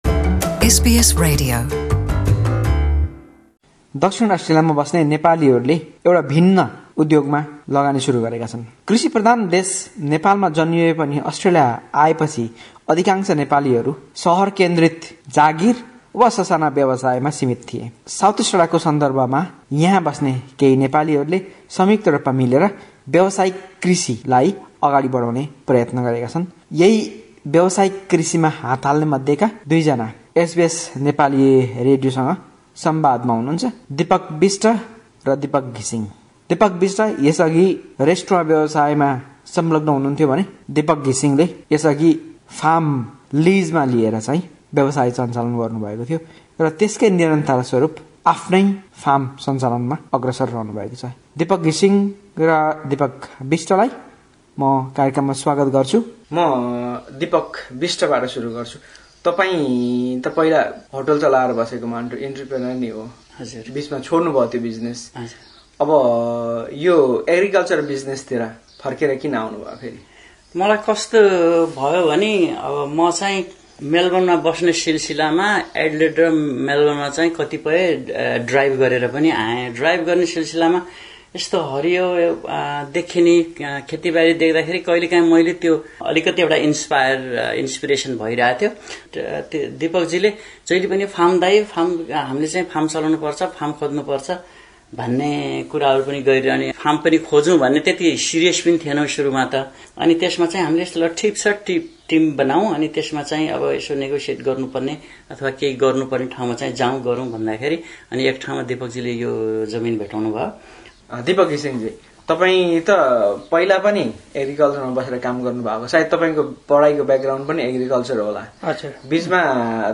व्यावसायिक खेतीलाई विस्तार गर्ने निकै ठूलो सपना बोकेका यी नेपाली कृषकहरुसितको छोटो कुराकानी ।